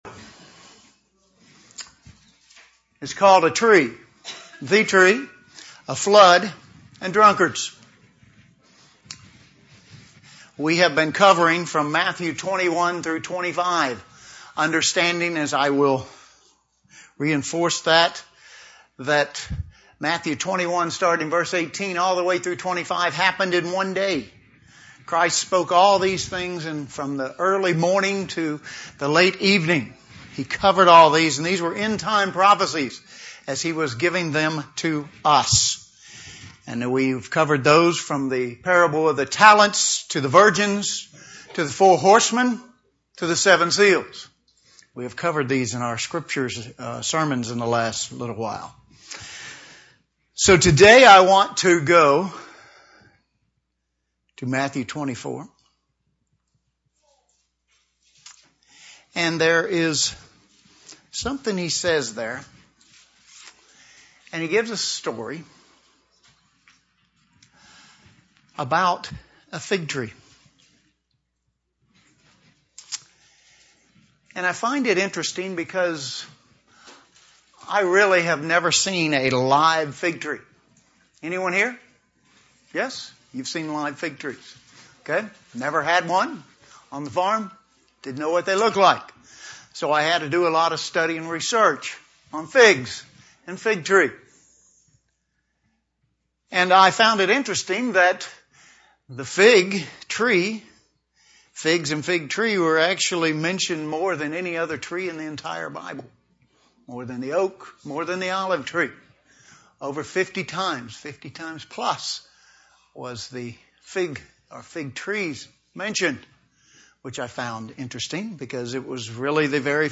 End-time events predicted by Jesus - Matthew 24: 32-51 UCG Sermon Transcript This transcript was generated by AI and may contain errors.